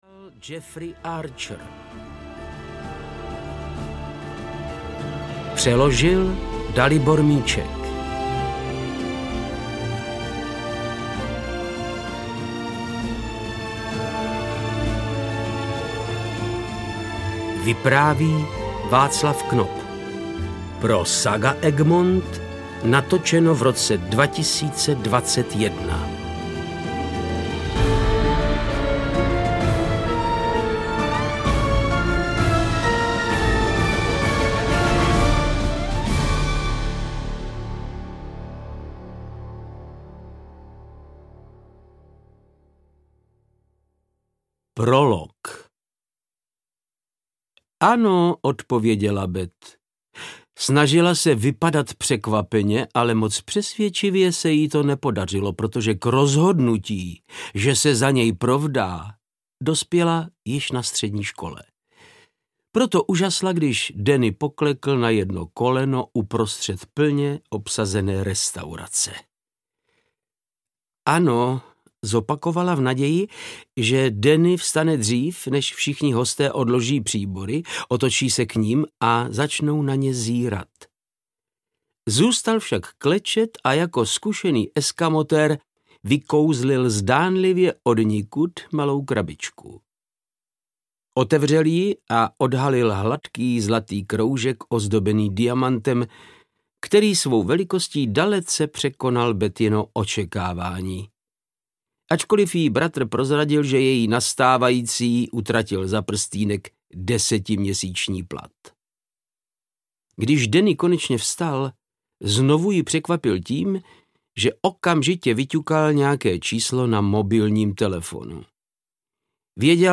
Čas pomsty audiokniha
Ukázka z knihy
• InterpretVáclav Knop